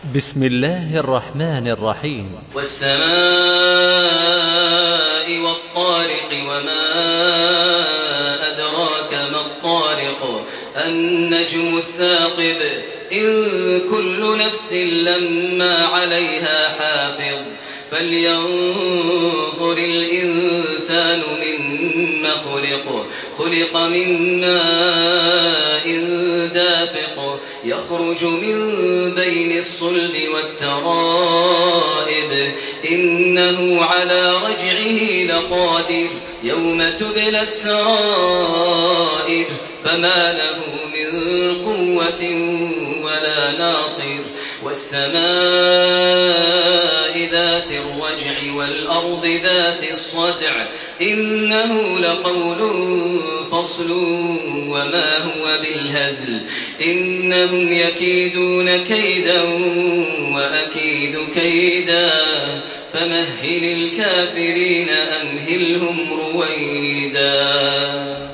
تحميل سورة الطارق بصوت تراويح الحرم المكي 1428
مرتل حفص عن عاصم